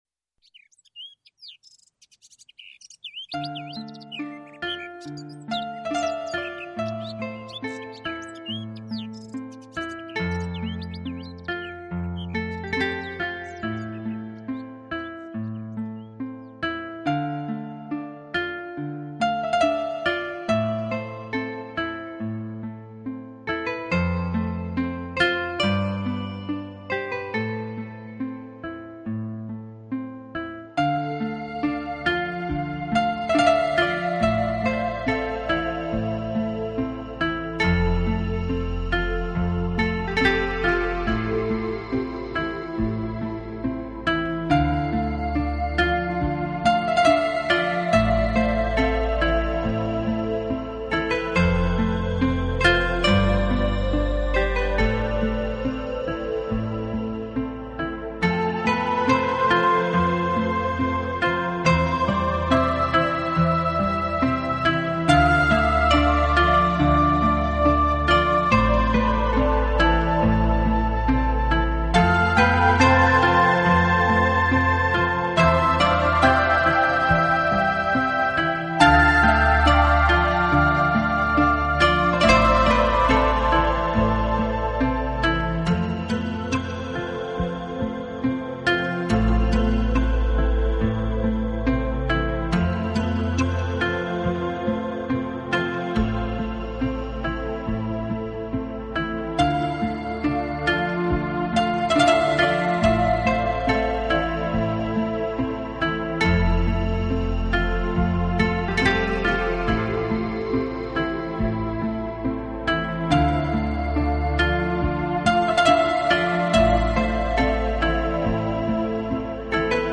【纯音乐】